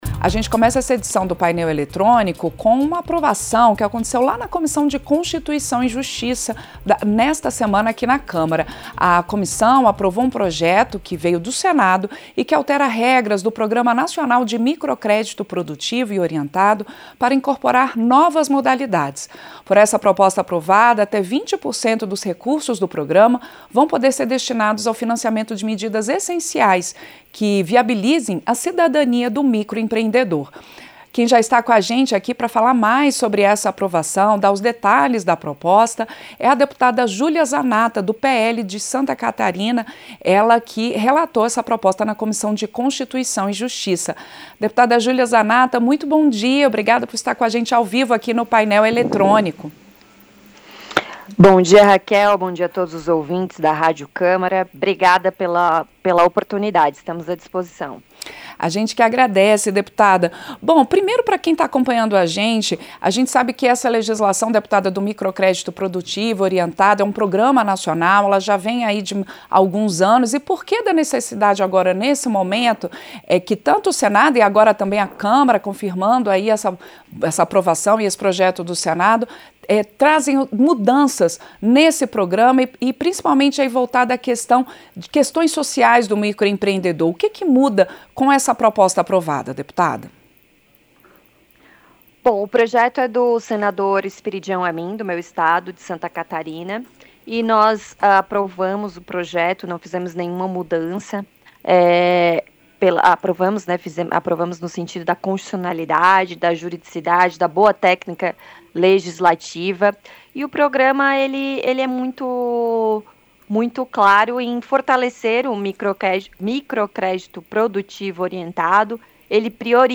• Entrevista - Dep. Júlia Zanatta (PL-SC)
Programa ao vivo com reportagens, entrevistas sobre temas relacionados à Câmara dos Deputados, e o que vai ser destaque durante a semana.